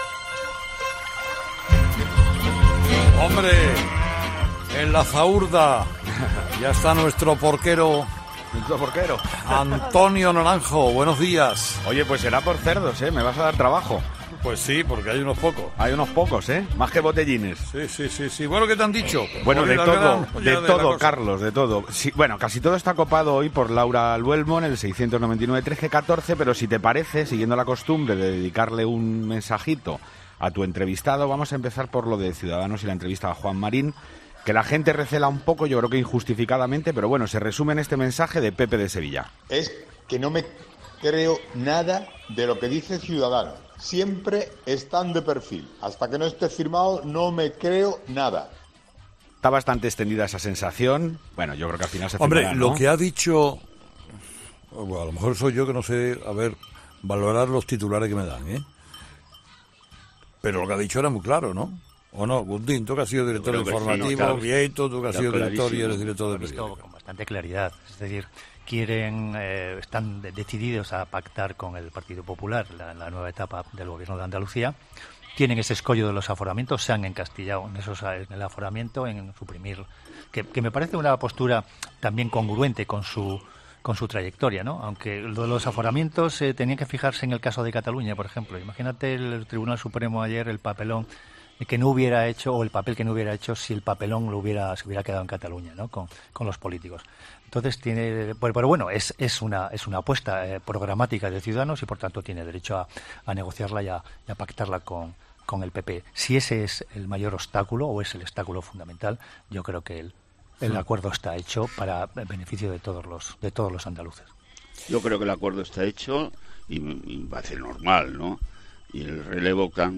La tertulia de los oyentes es el espacio que Carlos Herrera cede a sus seguidores para que pongan sobre la mesa sus opiniones sobre los temas de actualidad.